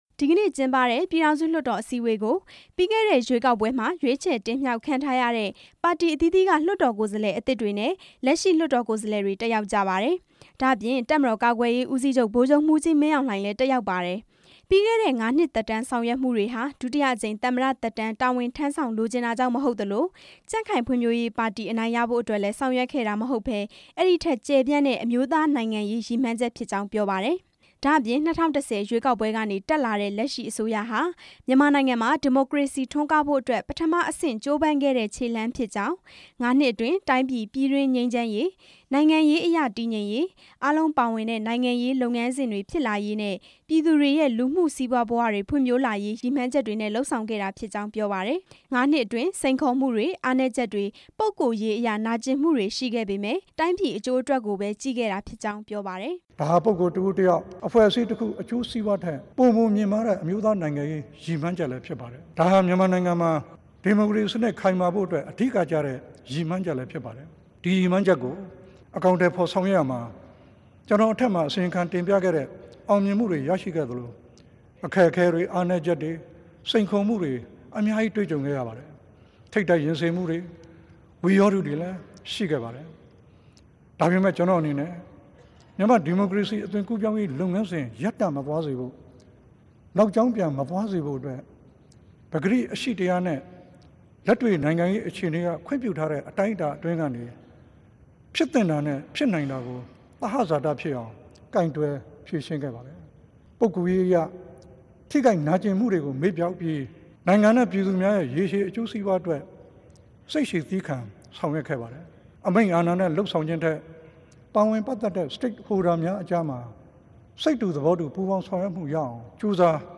ပြည်ထောင်စုလွှတ်တော်မှာပြောခဲ့တဲ့ ဦးသိန်းစိန်ရဲ့ မိန့်ခွန်း တင်ပြချက်